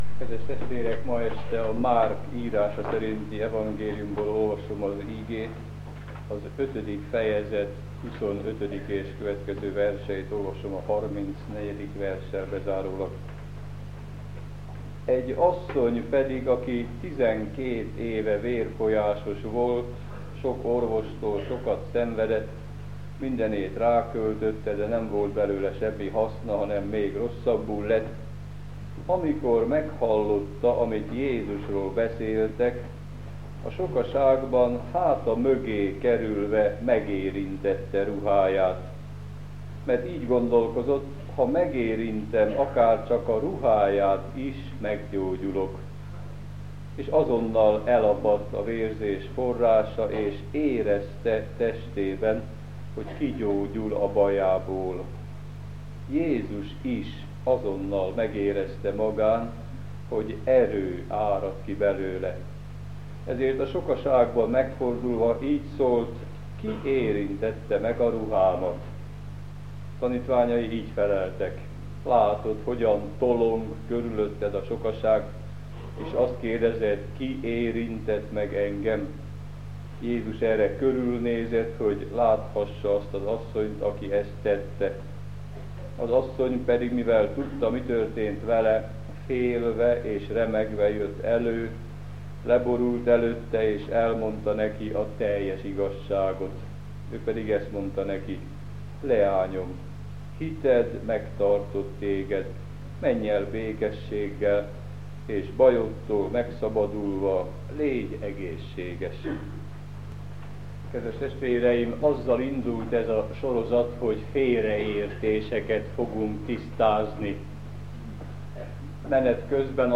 igehirdetései